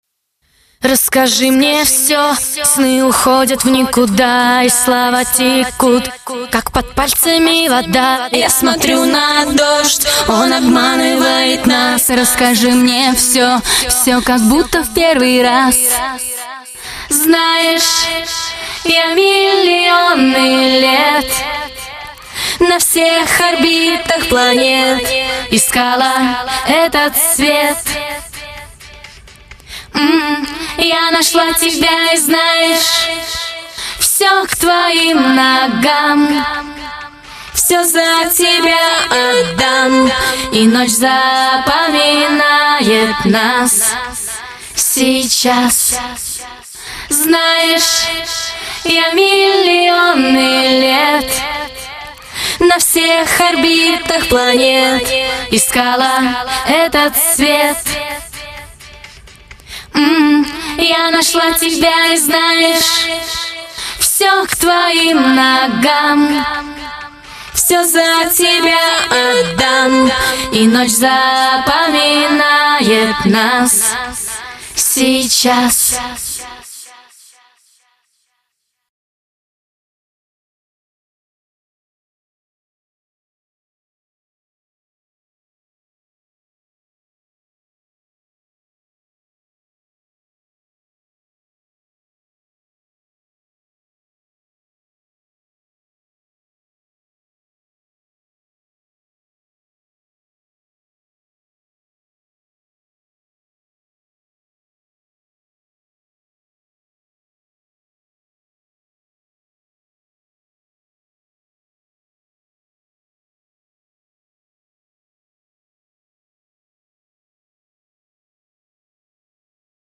Категория: Полная акапелла